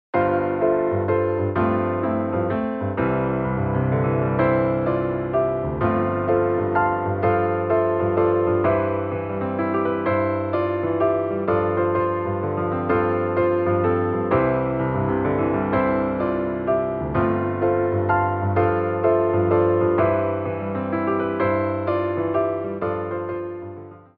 WALTZ